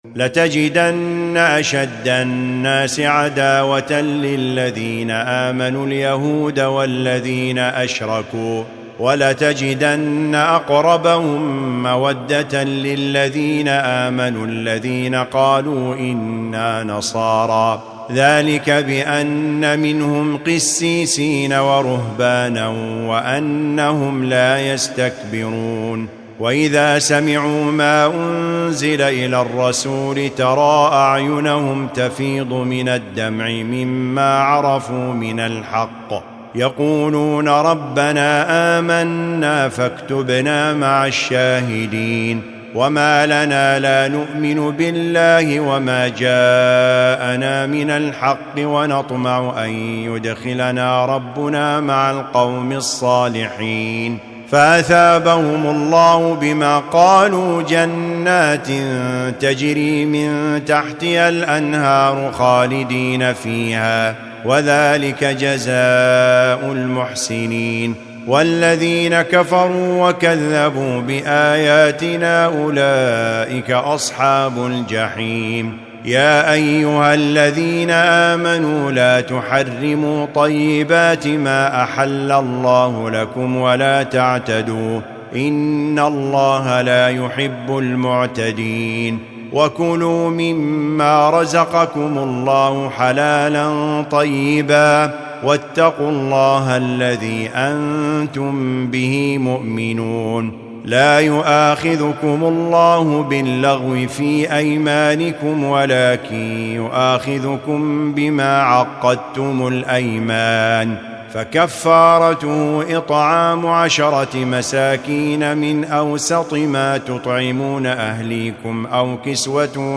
الجزء السابع : سورتي المائدة 82-120 و الأنعام 1-110 > المصحف المرتل